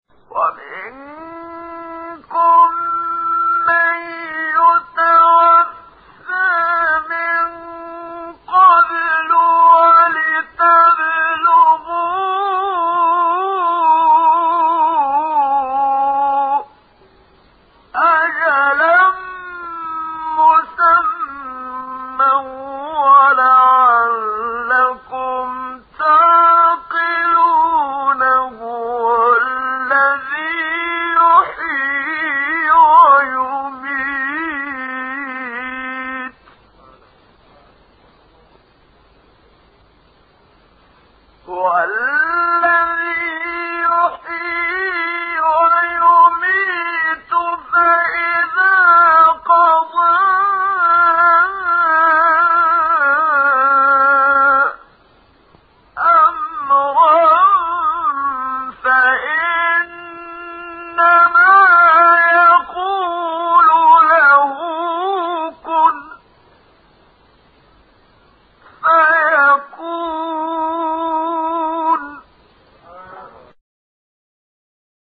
سایت-قرآن-کلام-نورانی-حجاز-منشاوی-3.mp3